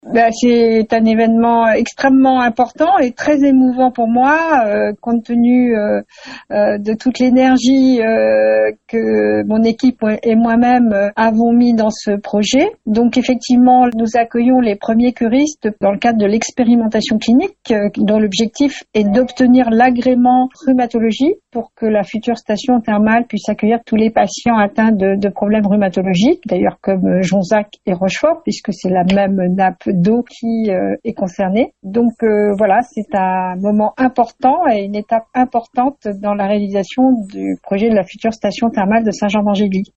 Un rêve qui devient enfin réalité pour la maire de la Ville Françoise Mesnard :